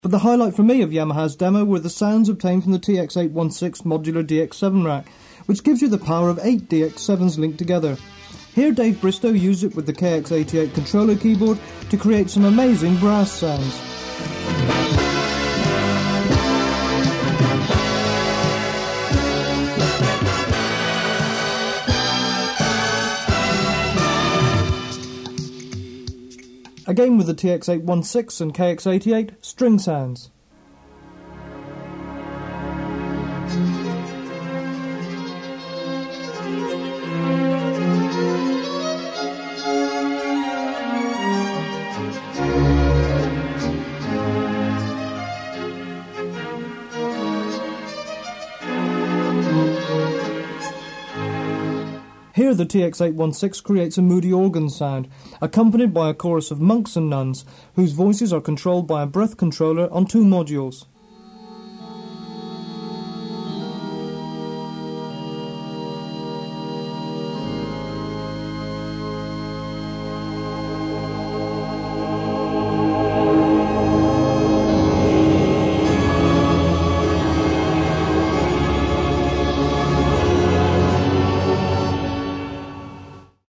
Tone Generator System Midi rack (1984)
demo 8 strings layer